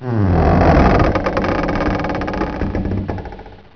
creak2a.wav